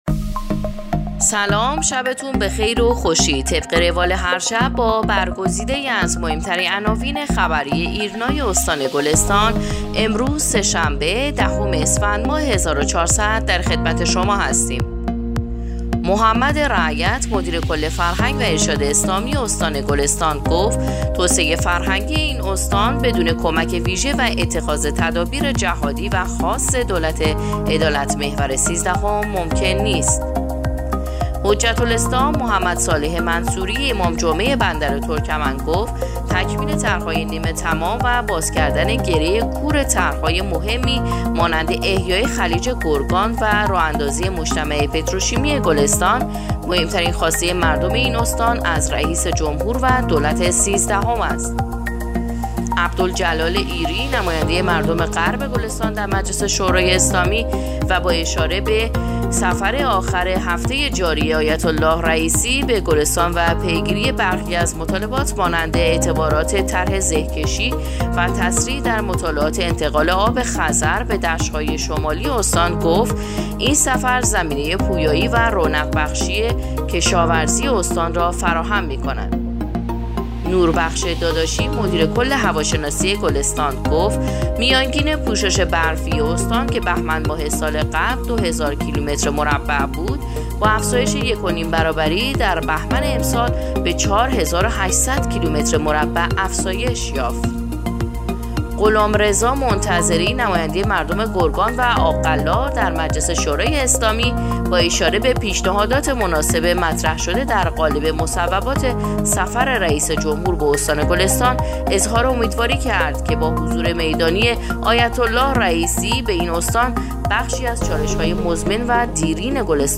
پادکست/ اخبار شبانگاهی دهم اسفندماه ایرنا گلستان